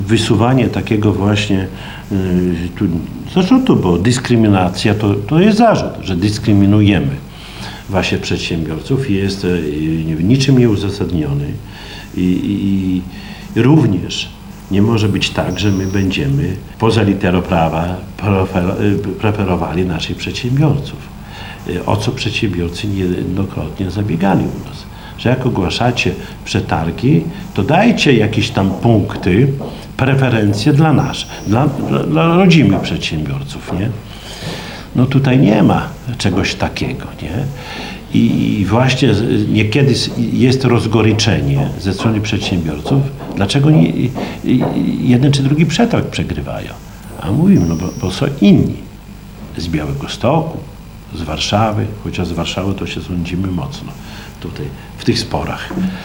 konferencja1.mp3